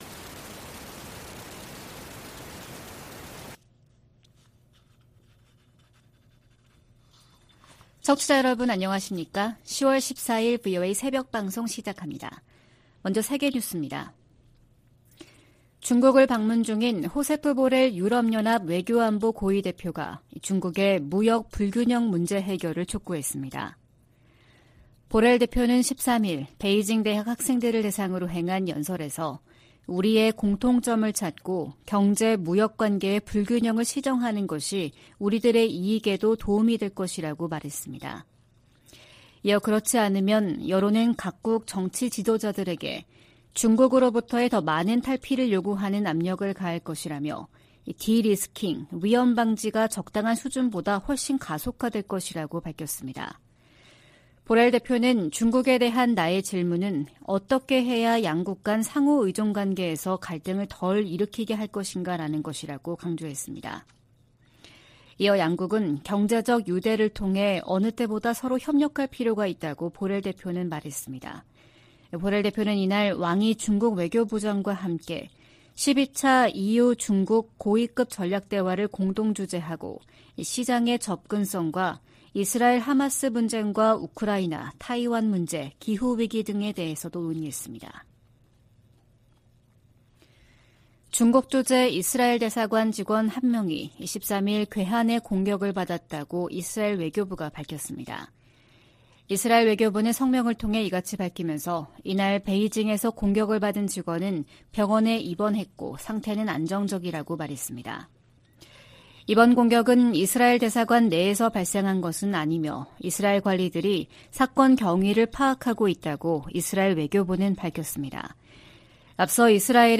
VOA 한국어 '출발 뉴스 쇼', 2023년 10월 14일 방송입니다. 이스라엘과 하마스의 전쟁 등 중동발 위기가 한반도에 대한 미국의 안보 보장에 영향을 주지 않을 것이라고 백악관이 밝혔습니다. 북한이 하마스처럼 한국을 겨냥해 기습공격을 감행하면 한미연합사령부가 즉각 전면 반격에 나설 것이라고 미국 전문가들이 전망했습니다. 중국 내 많은 북한 주민이 송환된 것으로 보인다고 한국 정부가 밝혔습니다.